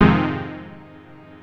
HOUSE 8-L.wav